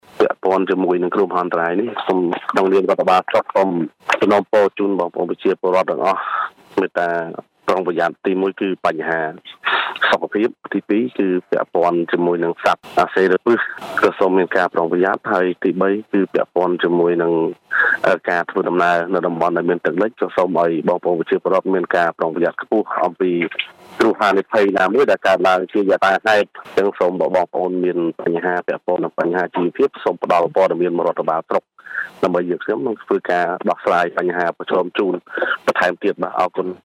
ប្រសាសន៍របស់លោក អ៊ូច សាវឿន អភិបាលស្រុកកណ្តាលស្ទឹង៖